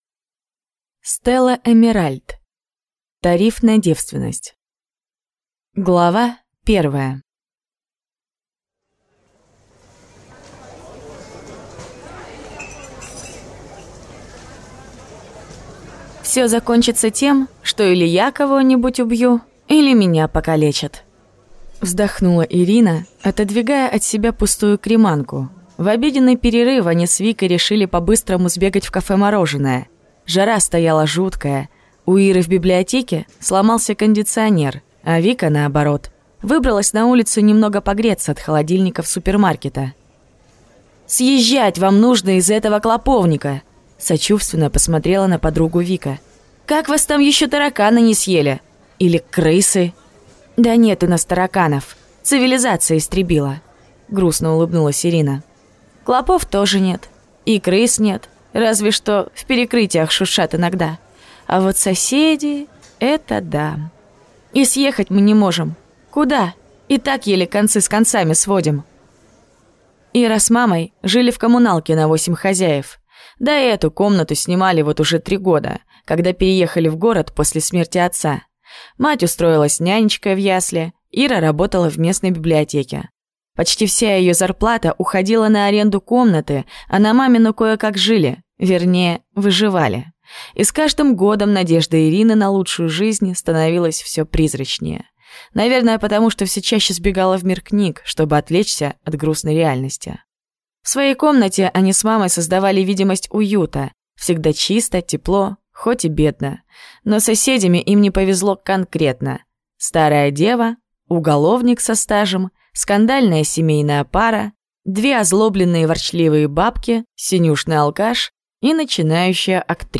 Аудиокнига Тариф на девственность | Библиотека аудиокниг